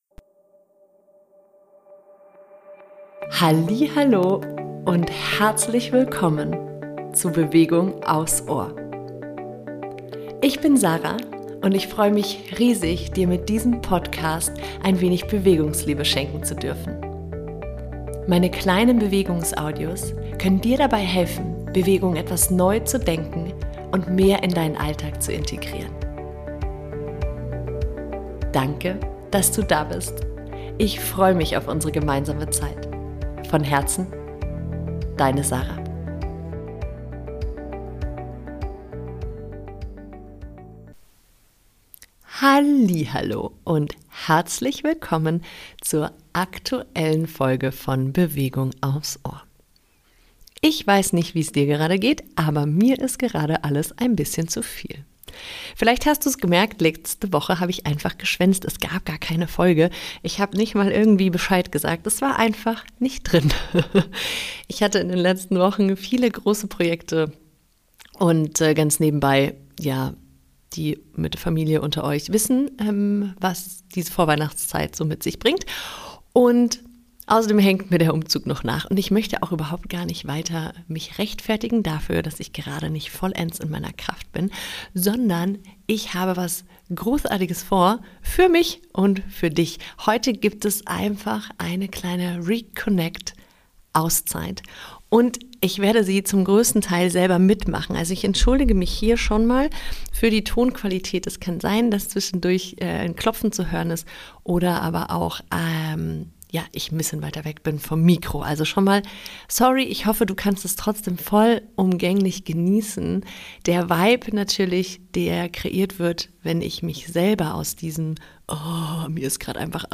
Beschreibung vor 1 Jahr In dieser Folge leite ich dich durch eine kurze Selbstliebe Session in der du mit sanften Berührungen, Tapping und deinem Atem die Verbindung zu dir wieder herstellst.